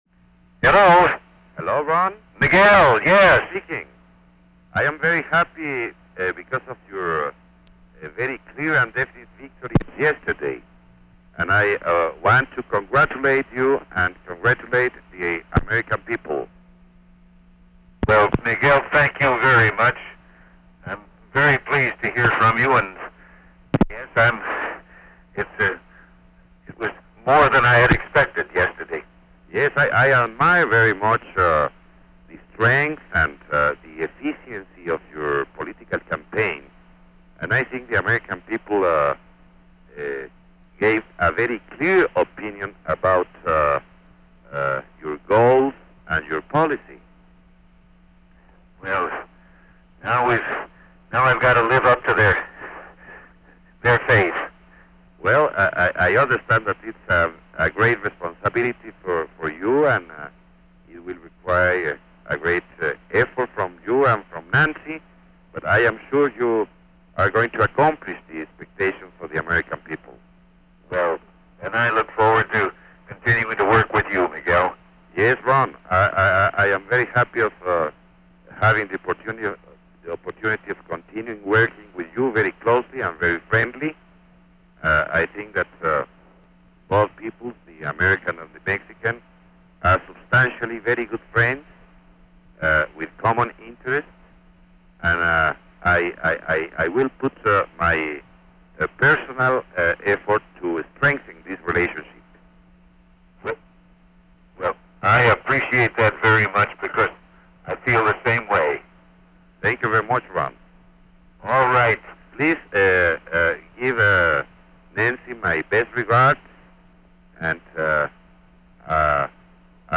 The Presidency / Featured Content 'Very Good Friends with Common Interests' 'Very Good Friends with Common Interests' Photo: National Archives and Records Administration Following his landslide electoral victory in the 1984 U.S. presidential election, President Reagan took a congratulatory phone call from Mexican president Miguel de la Madrid Hurtado. Both leaders expressed their desire to strengthen the relationship between Mexico and the United States. Date: November 7, 1984 Location: Situation Room Tape Number: Cassette 10A.3 Participants Ronald W. Reagan Miguel de la Madrid Hurtado Associated Resources Annotated Transcript Audio File Transcript